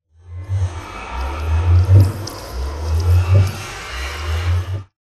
portal.ogg